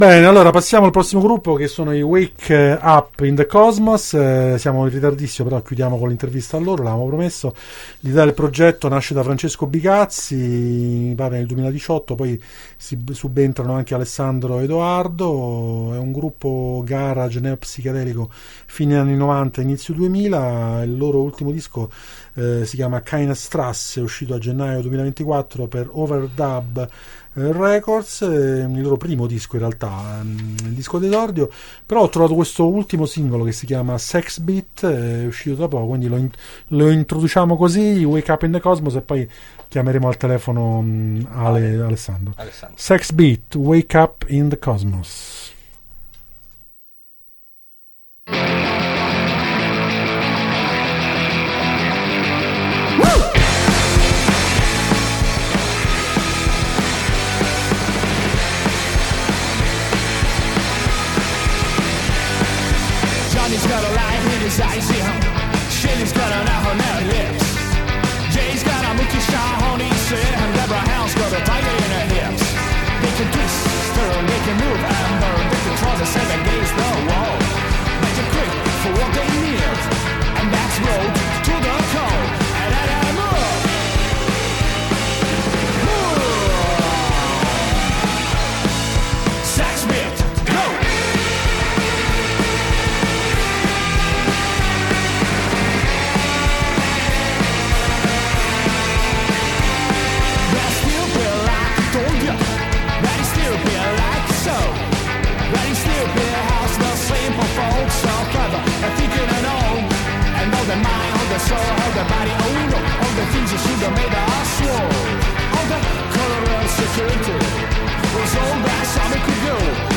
Intervista ai Wake Up in the Cosmos a “Drive in Saturday” del 15/2/2025 | Radio Città Aperta